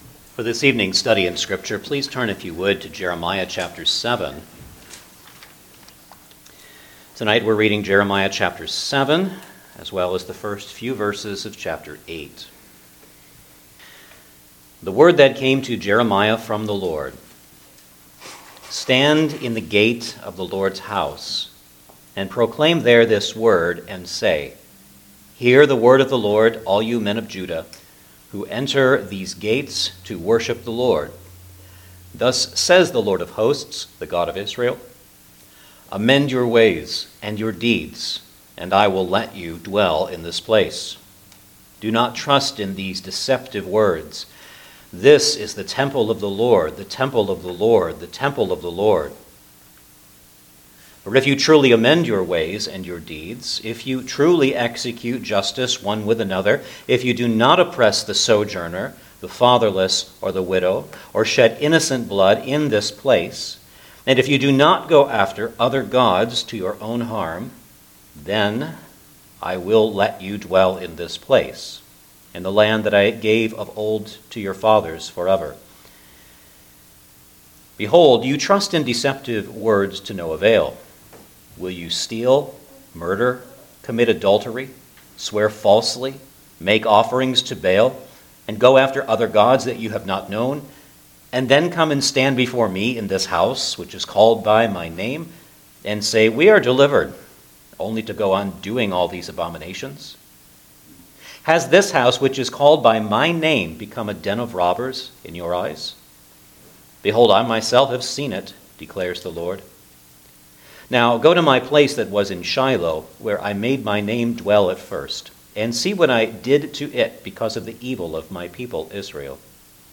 The Great Temple Sermon
Jeremiah Passage: Jeremiah 7:1 – 8:3 Service Type: Sunday Evening Service Download the order of worship here .